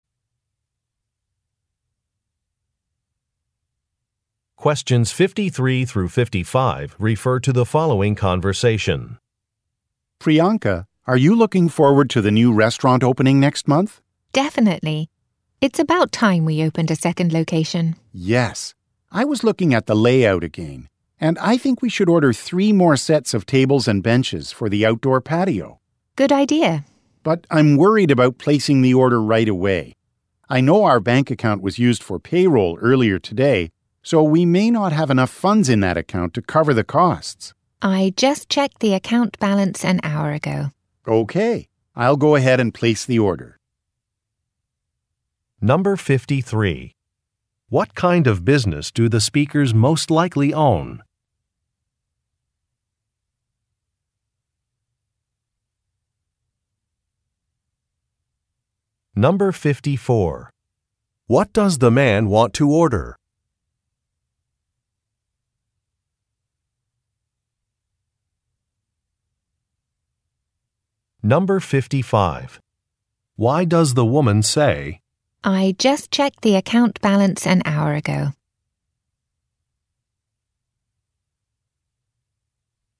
Question 53 - 55 refer to following conversation: